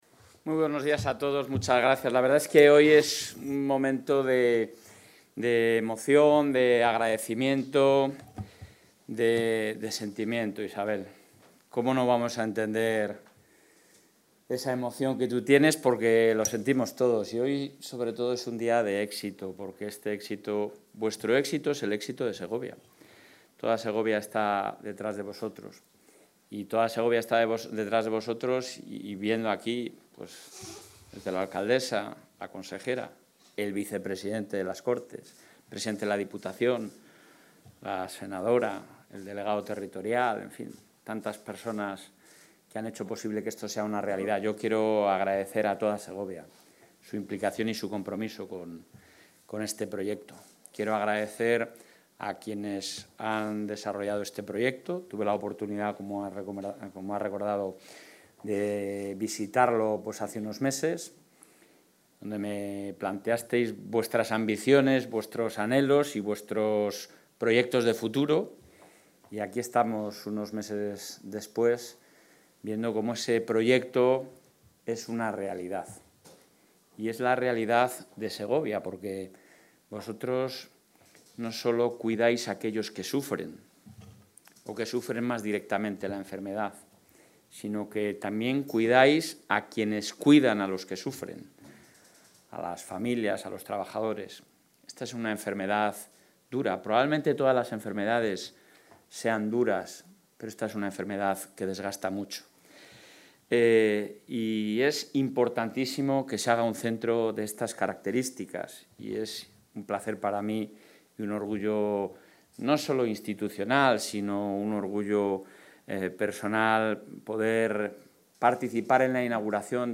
El presidente Alfonso Fernández Mañueco ha participado hoy en la inauguración de este nuevo espacio, que es fruto de la colaboración del Gobierno autonómico con las administraciones locales y todo el tejido social y empresarial
Intervención del presidente de la Junta